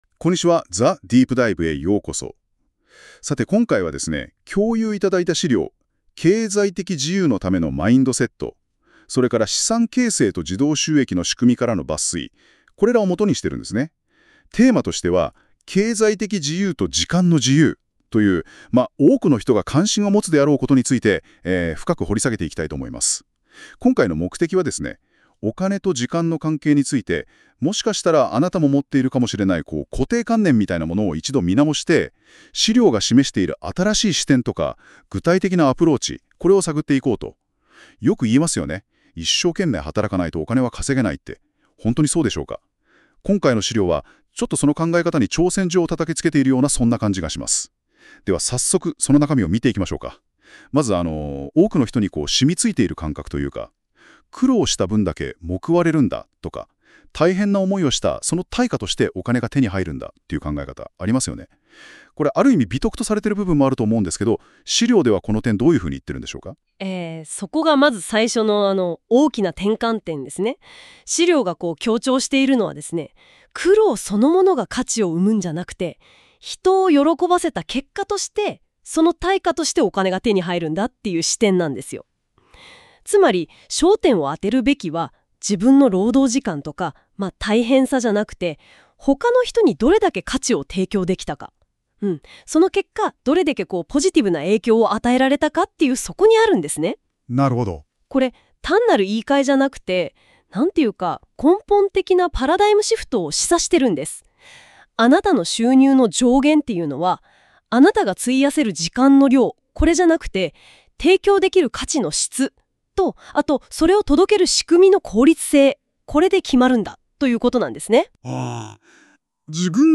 昨日の夜、NotebookLMに雑にWord1ページくらいにまとめた資料（文章のみ）を渡したら、ほぼ完ぺきに意図を理解して音声を生成してくれた。